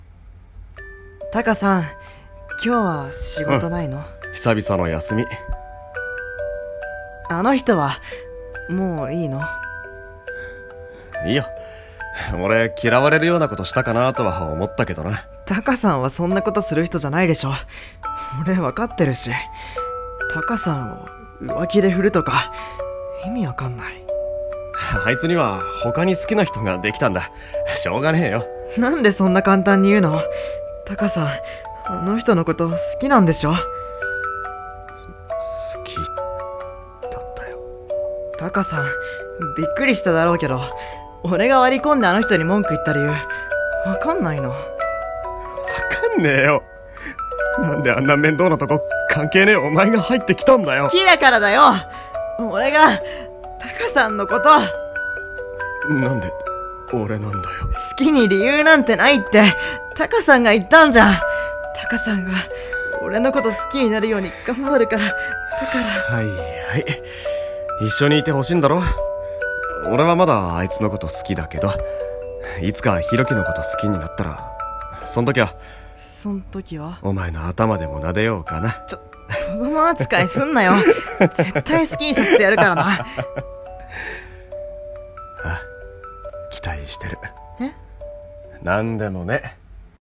《BL声劇》失恋